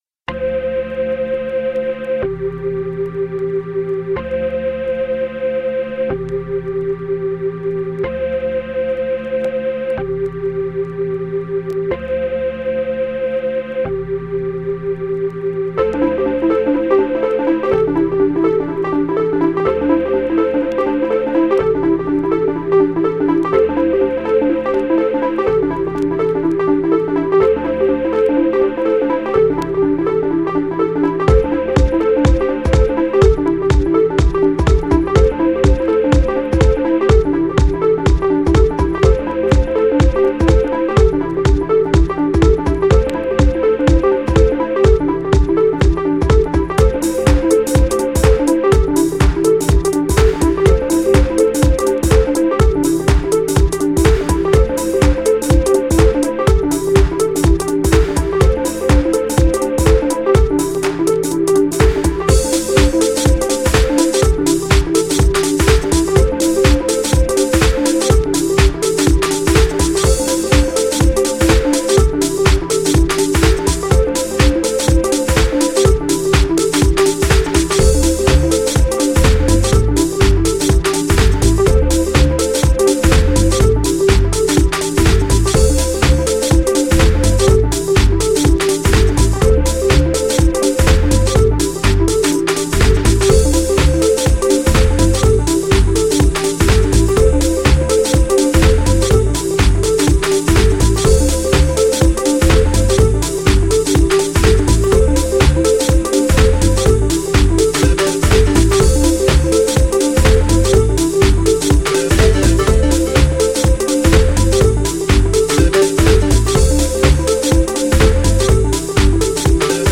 以前までと比べてダーク＆ハードな側面が強いフロア向けなサウンド!!
GENRE House
BPM 121〜125BPM